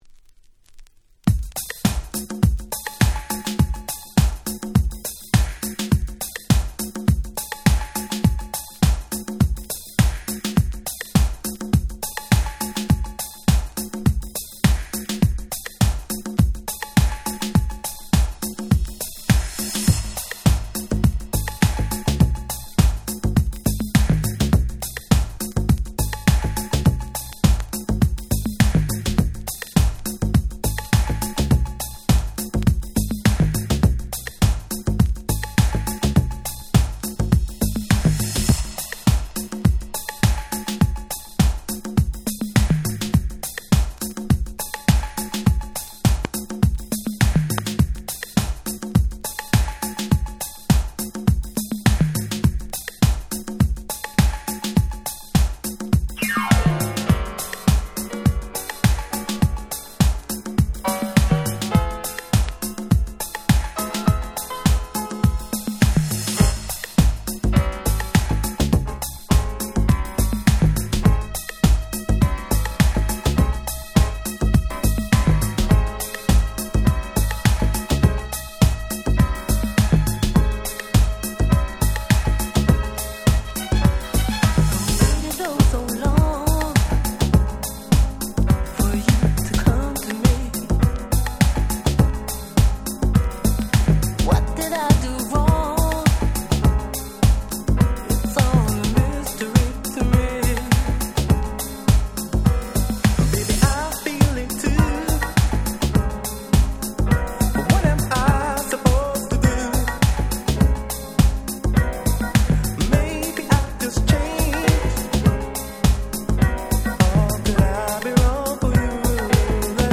最高のUK Soul / Disco Boogieです。